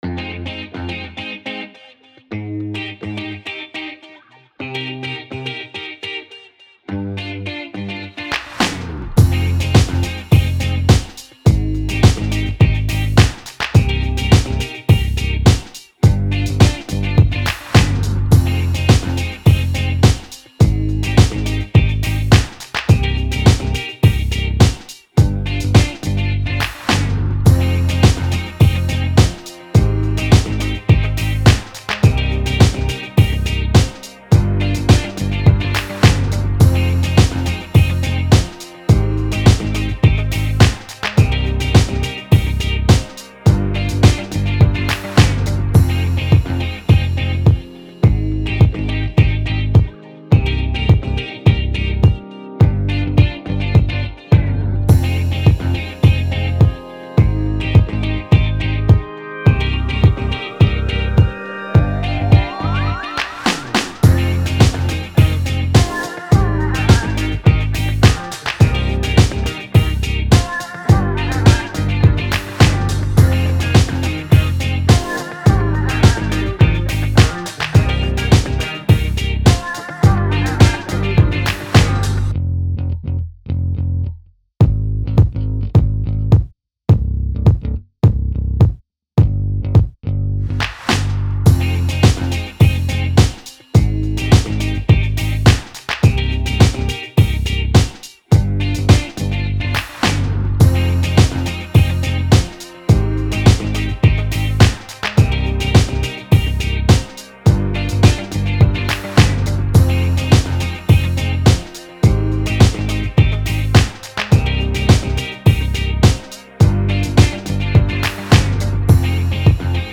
Pop, Retro, Dance
C Min